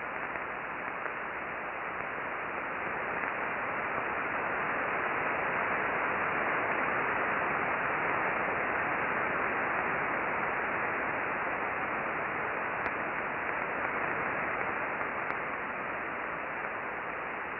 Solar Radio Observations for 07 June 2012
Click on the above chart for audio associated with the solar burst at 0509.